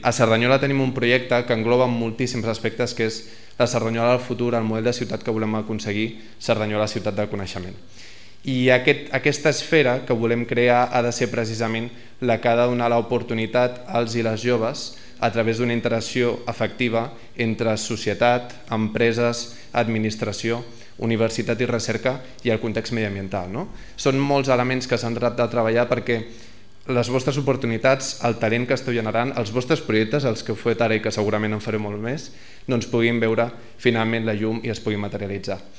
El lliurament dels premis, que es va fer a la Masia de Can Serraperera, forma part del Programa de Foment de la Cultura Emprenedora adreçat als instituts d’ensenyament secundari del municipi.
El regidor d’Educació,